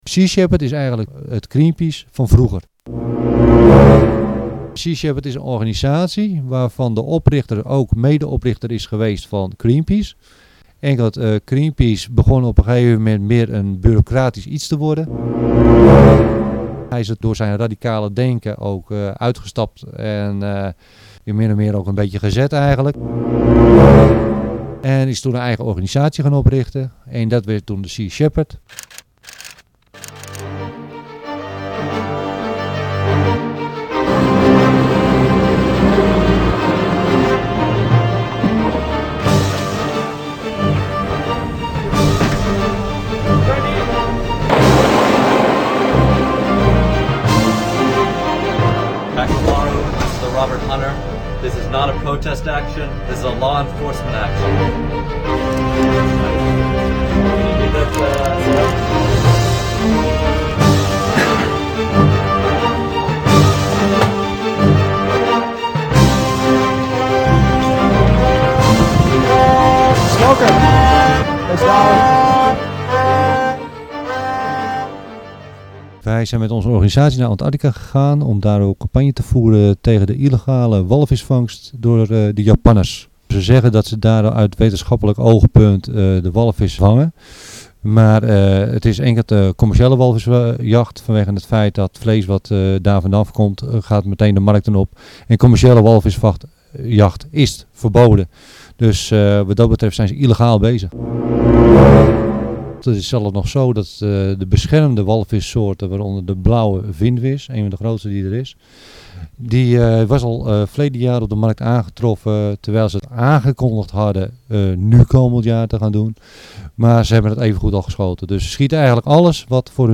In een intervieuw gaat hij in op zijn belevenissen.